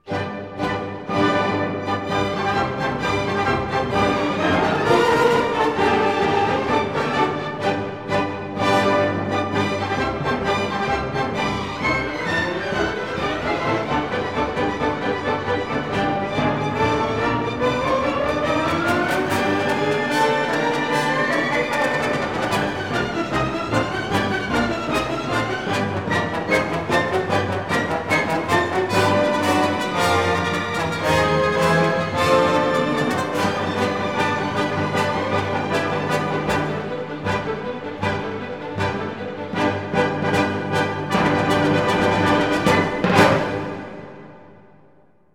Ce dernier lui inspira une partition pleine de joie et de poésie, que parachève une vertigineuse bacchanale qui ne laissera personne insensible.!